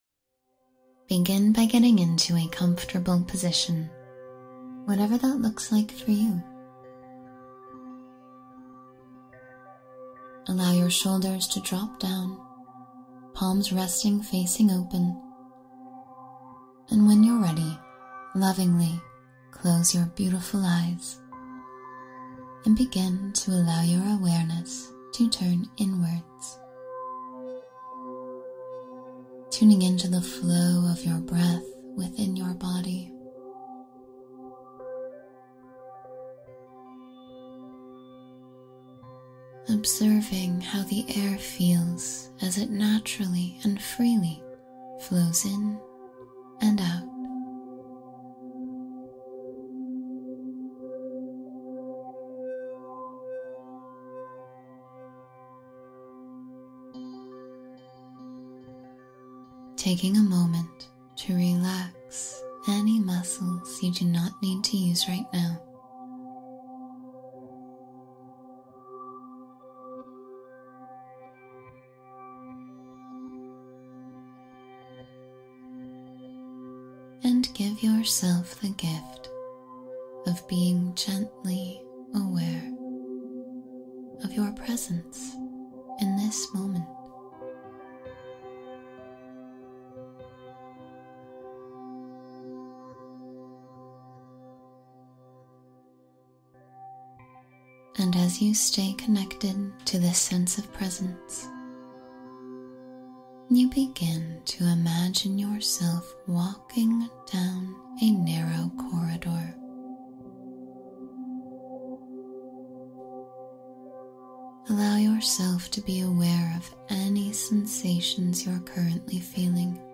Cleanse Negativity with White Light — Guided Meditation for Energy Purification